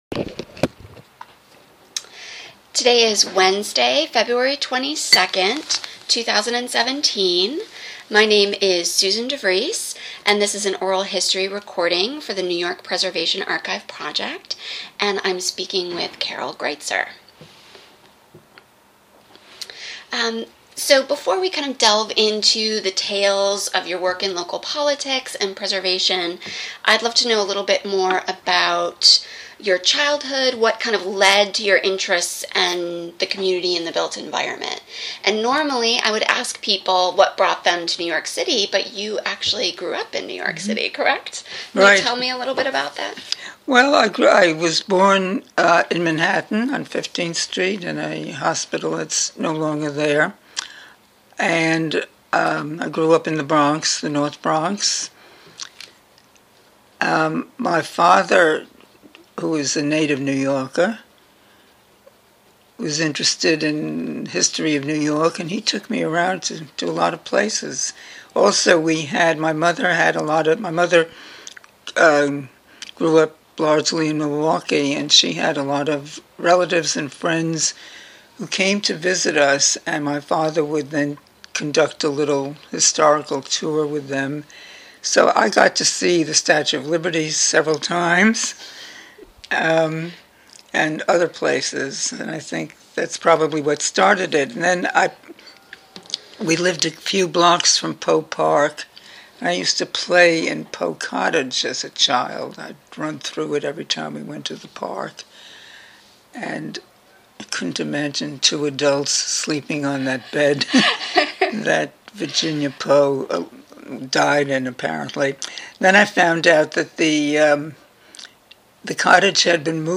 Carol Greitzer Oral History, Session 1
Former New York City Council member Carol Greitzer speaks about her time as a community advocate in Greenwich Village, both as a volunteer and city councilwoman, from the 1950s to the 1990s.